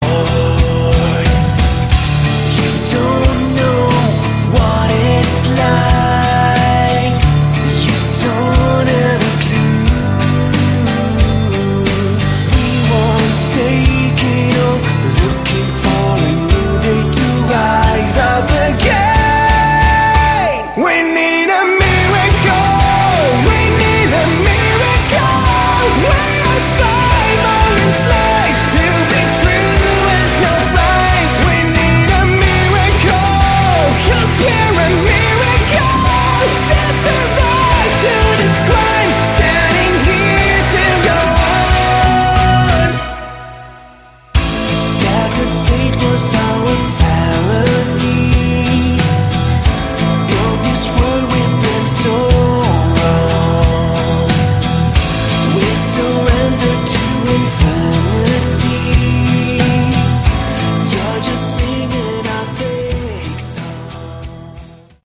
Category: Sleaze Glam
vocals
bass
drums
guitar